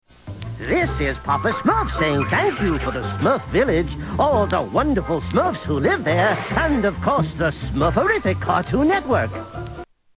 Tags: Holiday Thansgiving Cartoons Thanksgiving Cartoon clips Thansgiving clips